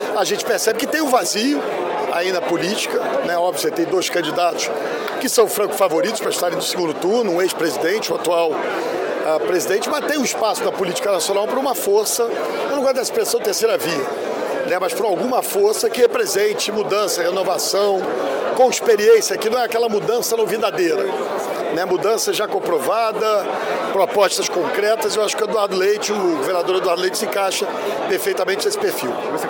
Durante a cerimônia de filiação de novos integrantes do PSD, em um hotel, no Centro do Rio, neste domingo, o prefeito Eduardo Paes declarou que o governador Eduardo Leite, do Rio Grande do Sul, foi convidado para ser o candidato do partido à presidência da República.